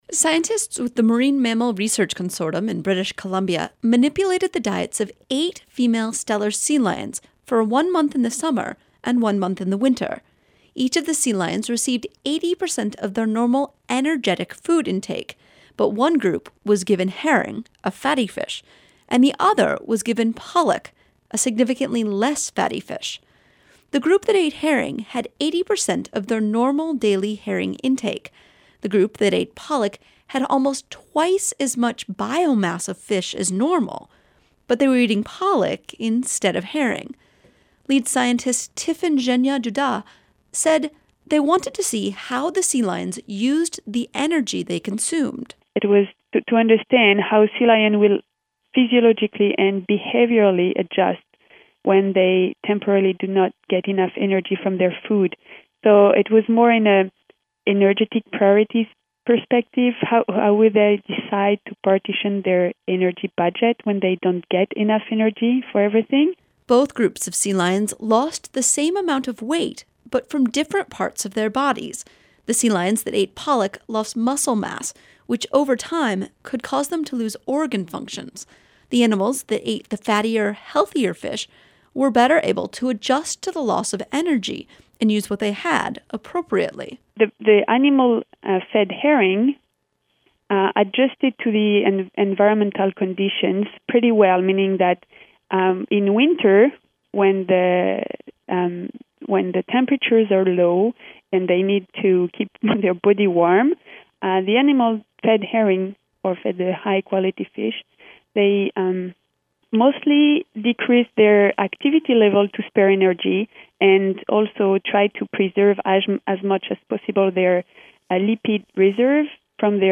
spoke with one of the lead researchers.